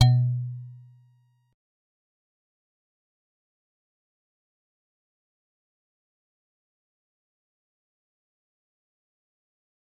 G_Musicbox-B2-pp.wav